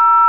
TONE0.WAV